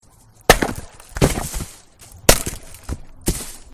chopwood.ogg